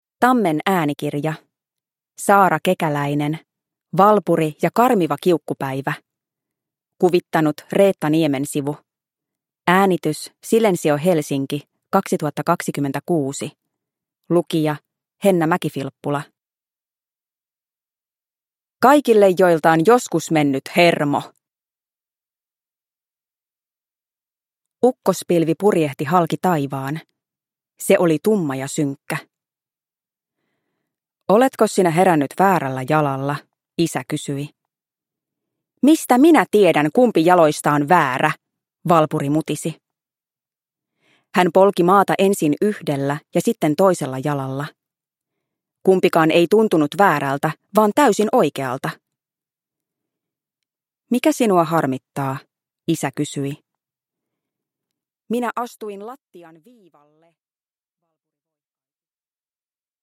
Valpuri ja karmiva kiukkupäivä (ljudbok) av Saara Kekäläinen